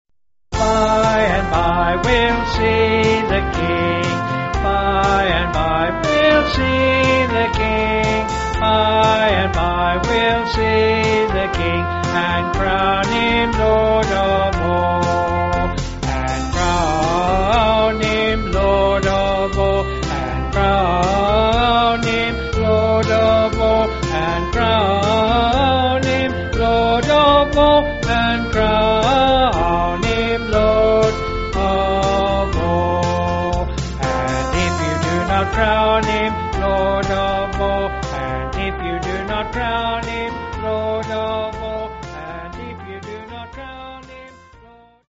Vocals and Kid's Club music